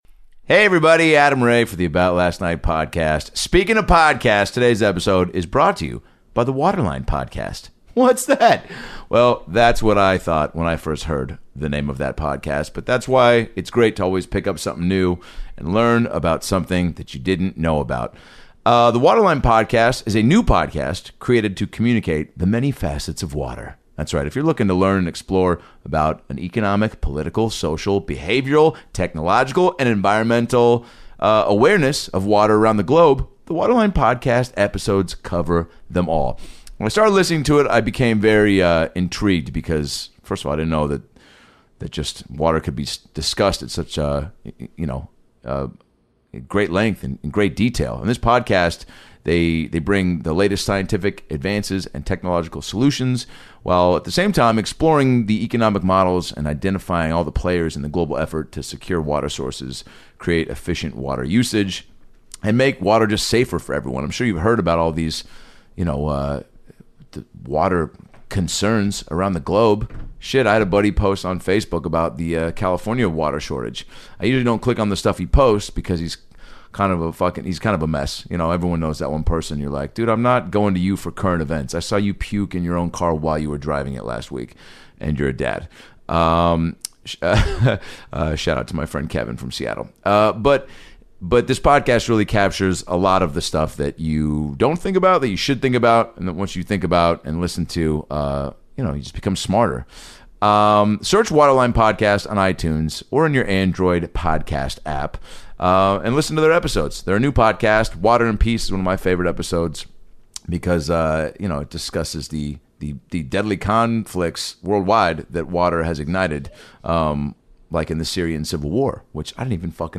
The AMAZING Jeff Garlin returns to the podcast to talk about his recent trip to Disneyland, some experiences he's had on Curb Your Enthusiasm, his stand up, losing his virginity to a heckler, what it takes to be successful, and his friend Jimmy Buffett chimes in throughout the podcast!